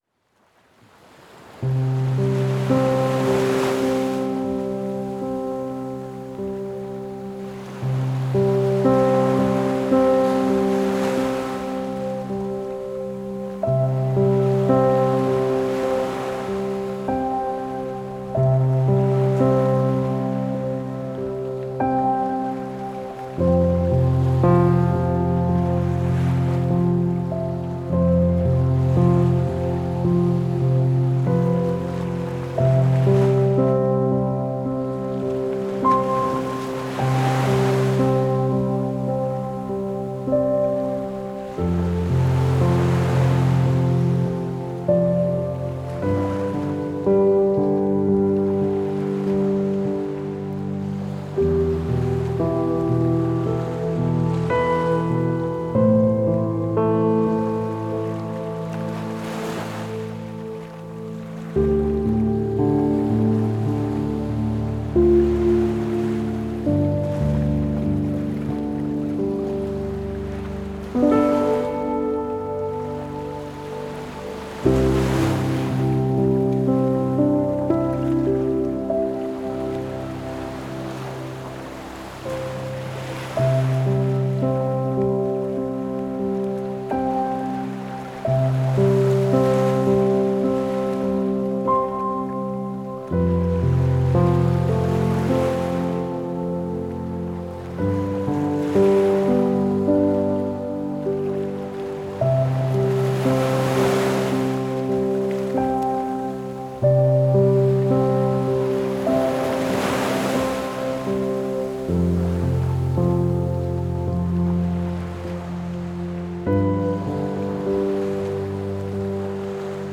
音乐风格；Pop